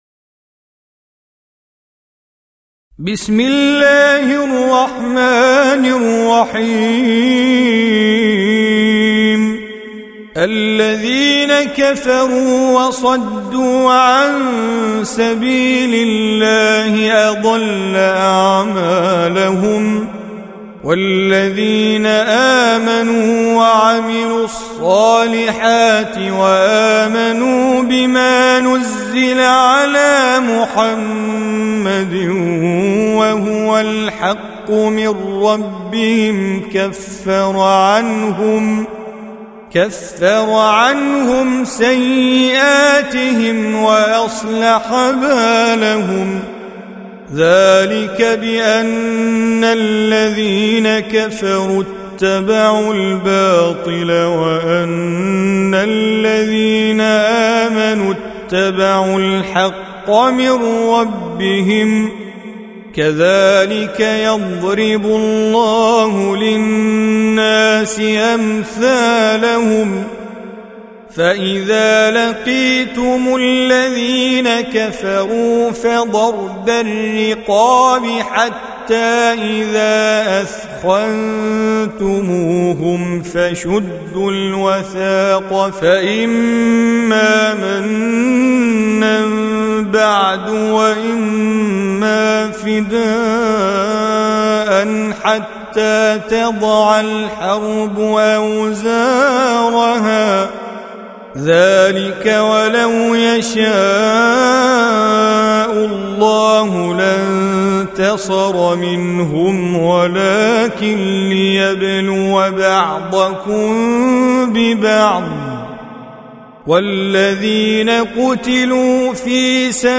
Surah Repeating تكرار السورة Download Surah حمّل السورة Reciting Mujawwadah Audio for 47. Surah Muhammad or Al-Qit�l سورة محمد N.B *Surah Includes Al-Basmalah Reciters Sequents تتابع التلاوات Reciters Repeats تكرار التلاوات